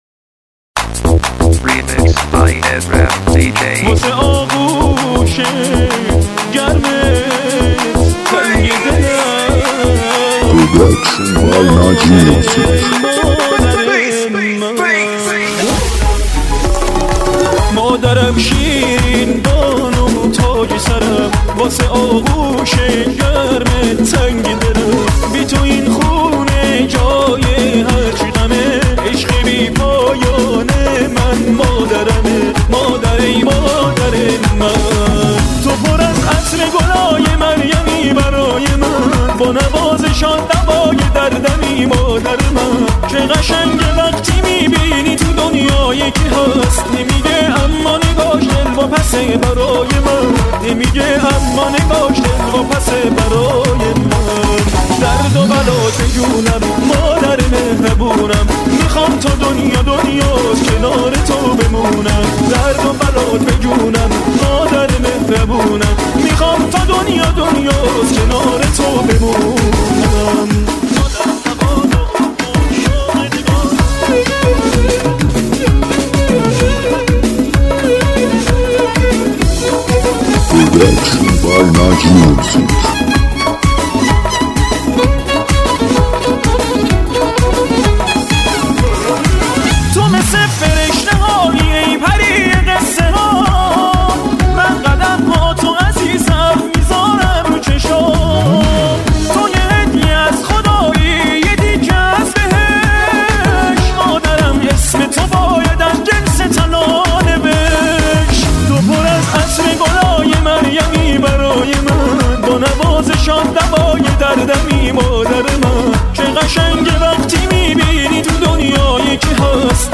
بیس دار تند شاد